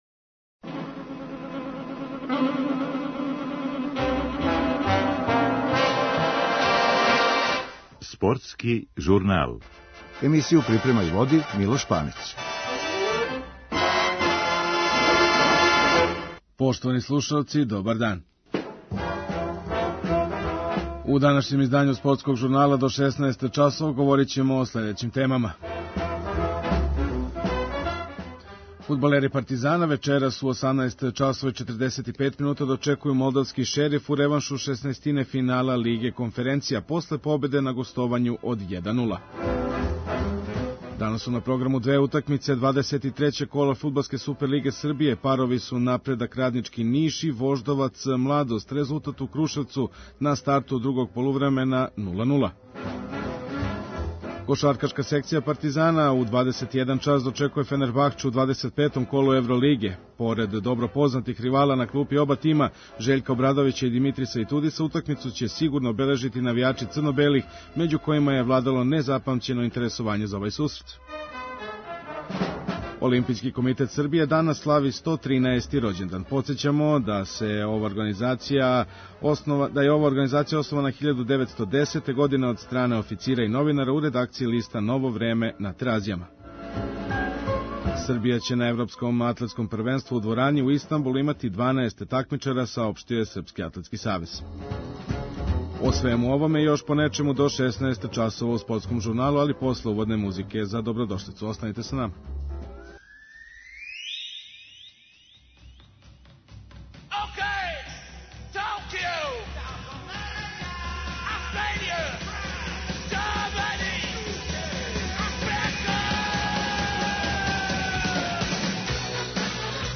Фудбалери Партизана вечерас у 18 часова и 45 минута дочекују молдавски Шериф у реваншу шеснаестине финала Лиге Конференција, после победе на гостовању од 1:0. Чућете изјаве чланова стручног штаба и првотицмаца црно-белих уочи веома важног меча за продужавање европске сезоне јединог преосталог српског клуба у УЕФА такмичењима.